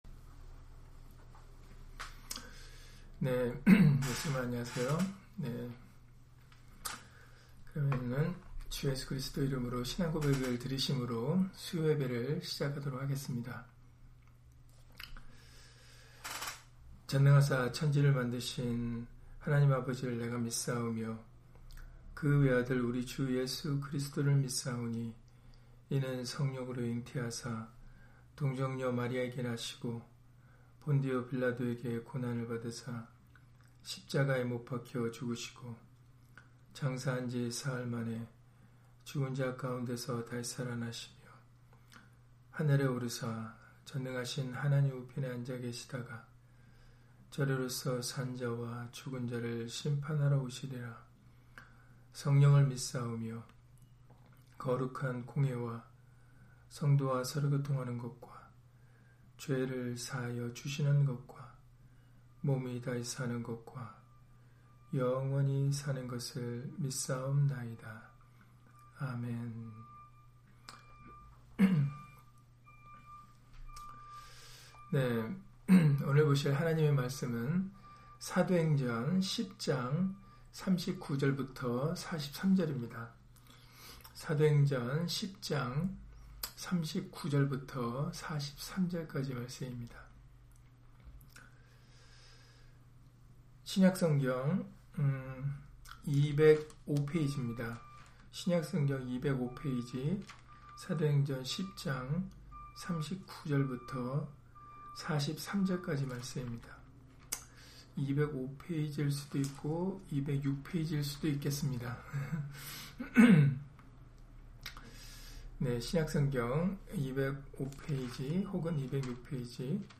사도행전 10장 39-43절 [전도에 대하여] - 주일/수요예배 설교 - 주 예수 그리스도 이름 예배당